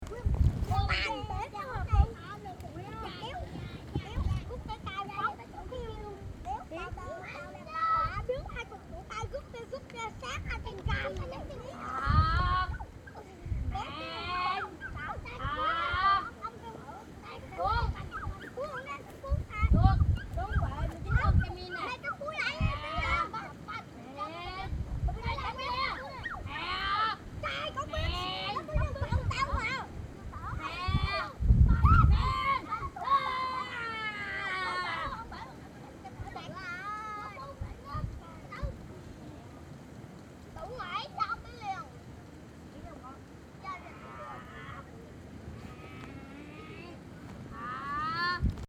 01/04/2014 16:00 Pour faire cette petite aquarelle, je m'installe sur une structure en bambou posée sur la plage. Manque de bol, quelques minutes plus tard, les enfants du village voisins décident d'organiser un tournoi de kung-fu imaginaire sur cette plateforme souple et mobile.